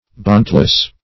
Bonnetless \Bon"net*less\, a. Without a bonnet.